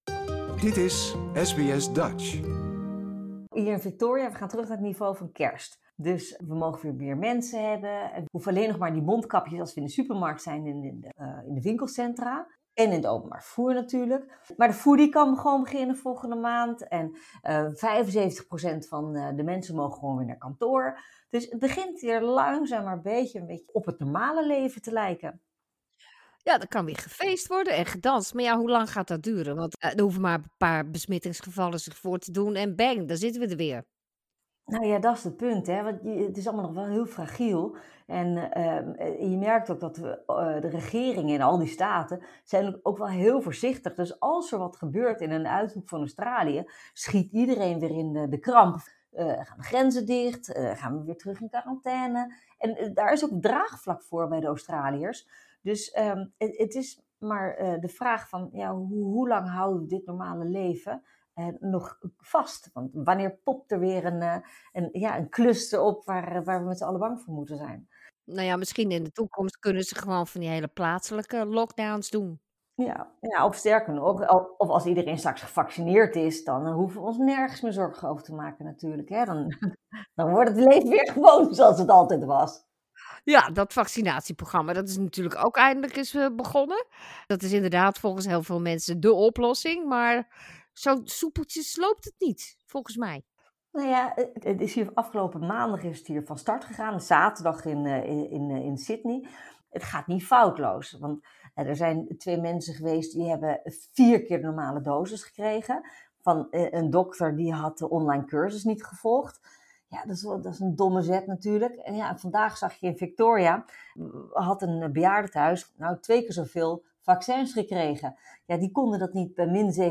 Politiek commentator Nicolien van Vroonhoven neemt de week die was even met SBS Dutch door en kijkt onder meer naar de coronaversoepelingen, de problemen met de eerste vaccins en de nijpende bejaardenzorg. Ook trekt zij vergelijkingen met haar eigen begintijd bij de Nederlandse Tweede Kamer en de macho cultuur hier in Canberra.